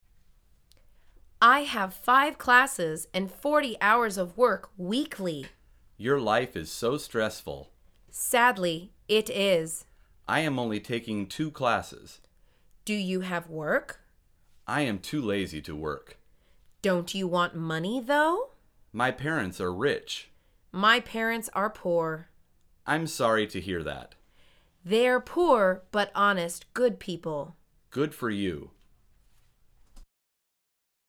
در واقع، این مکالمه مربوط به درس شماره یازدهم از فصل زندگی محصلی از این مجموعه می باشد.
توصیه می کنیم که با تمرین و تکرار این مکالمه را حفظ کرده و با فایل صوتی قرار داده شده در این مطلب تلاش به تقویت تلفظ زبان انگلیسی خود کنید.